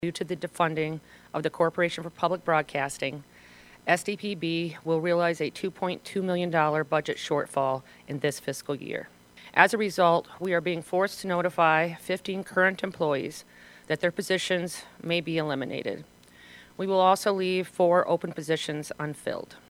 AT A NEWS CONFERENCE IN SIOUX FALLS: